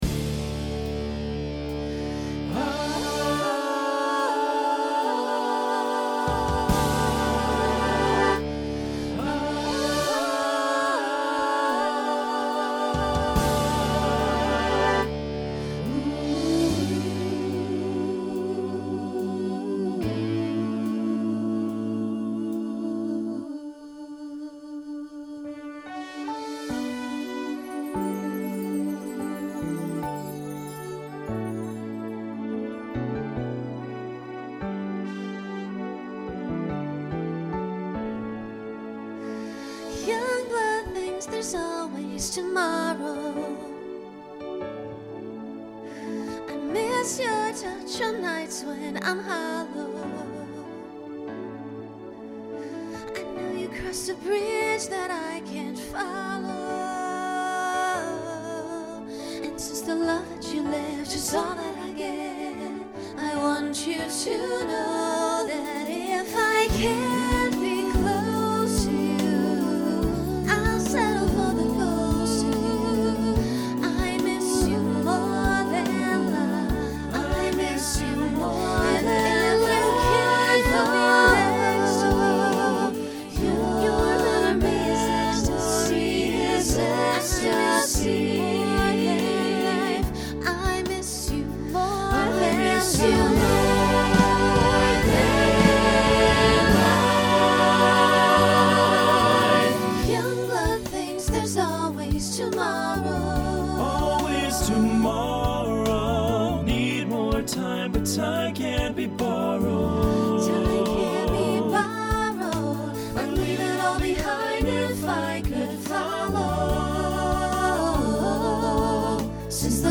Pop/Dance
Ballad Voicing SATB